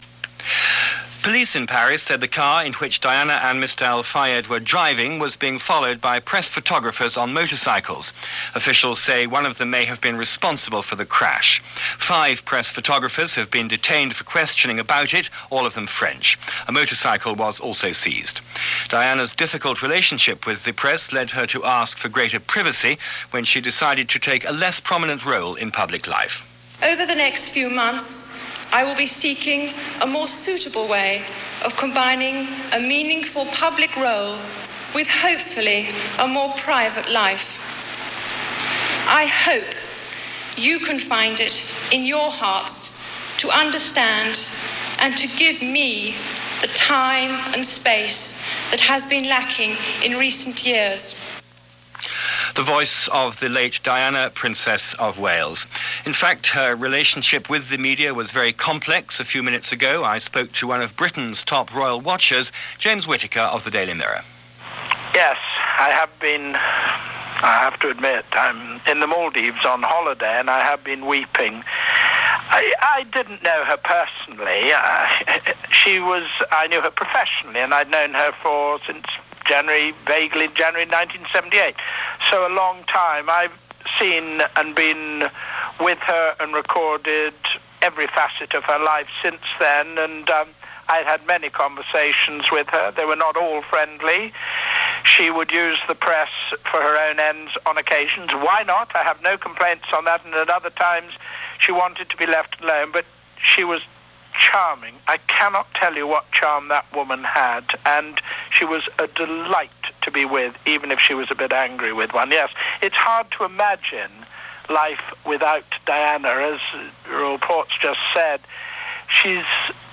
BBC World Service News -7:00 am